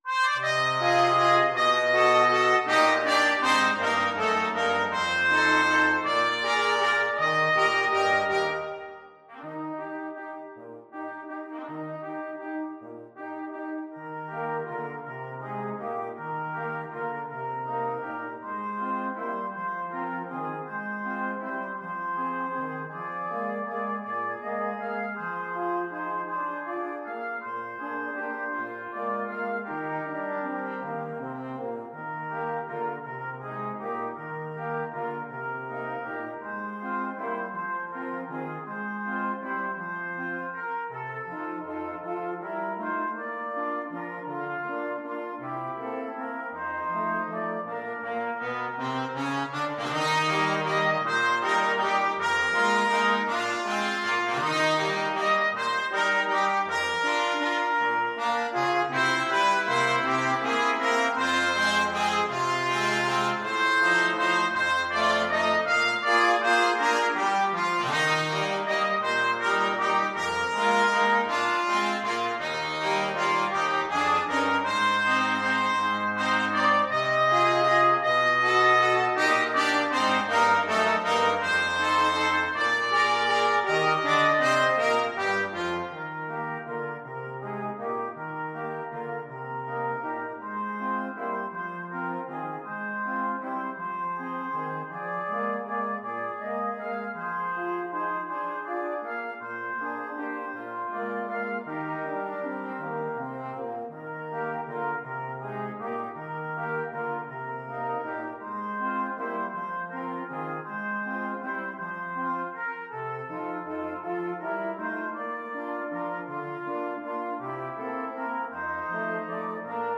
Trumpet 1Trumpet 2French HornTrombone
= 160 Tempo di valse = c.120
3/4 (View more 3/4 Music)
Pop (View more Pop Brass Quartet Music)